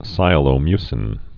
(sīə-lō-mysĭn, sī-ălō-)